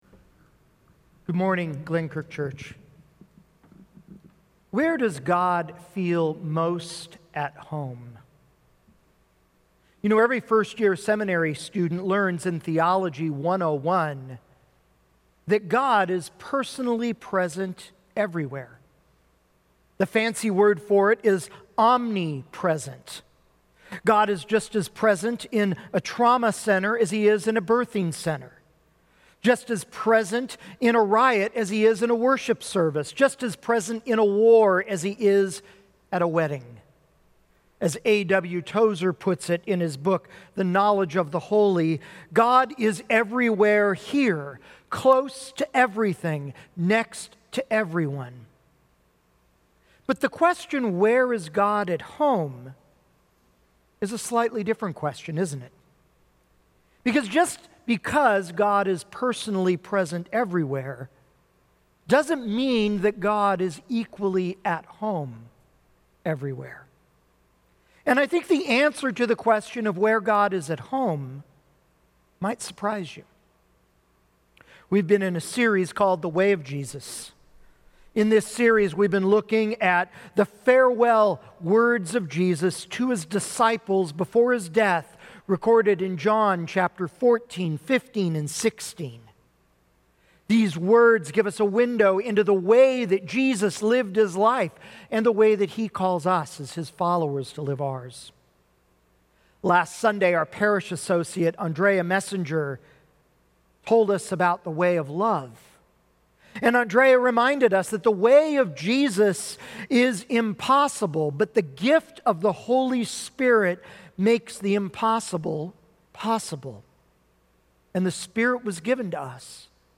June 7, 2020 – The Way of Jesus: Home – Glenkirk Church